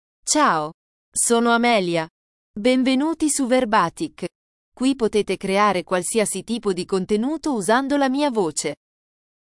AmeliaFemale Italian AI voice
Amelia is a female AI voice for Italian (Italy).
Voice sample
Listen to Amelia's female Italian voice.
Female
Amelia delivers clear pronunciation with authentic Italy Italian intonation, making your content sound professionally produced.